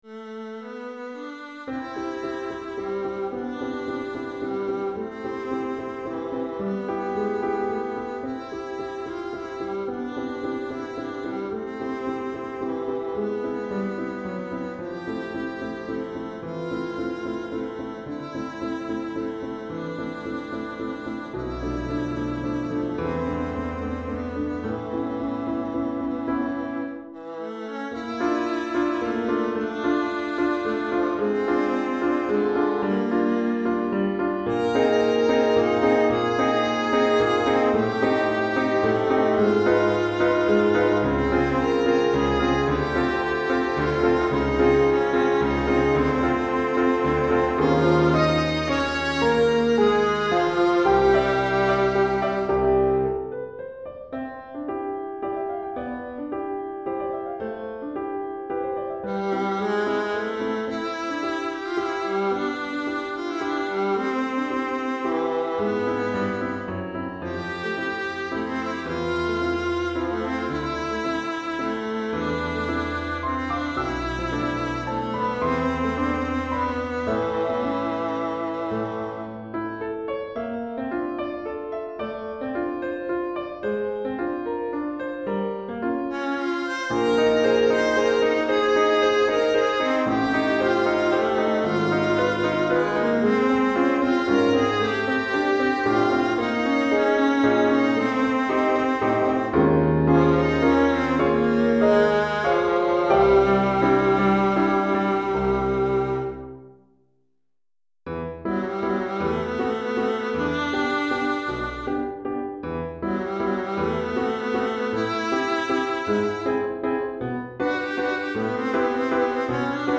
Alto et Piano